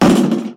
impact-4.mp3